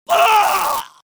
Screams Male 04
Screams Male 04.wav